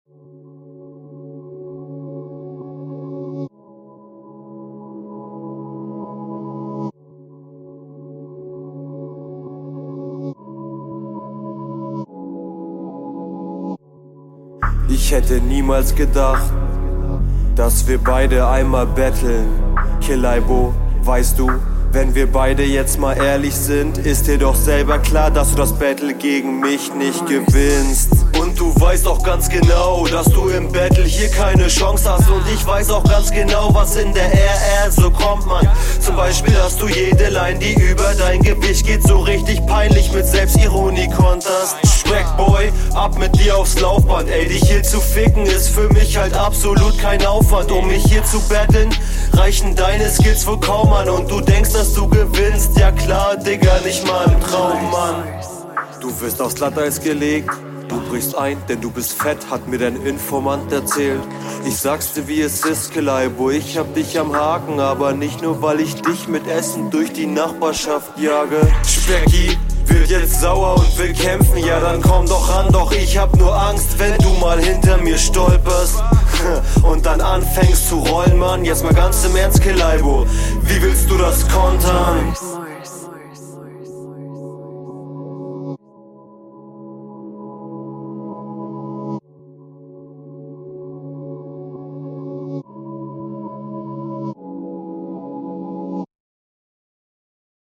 interessantes Soundbild. du könntest die Stimme noch etwas selbstbewusster einsetzen aber ein guter Ansatz ist …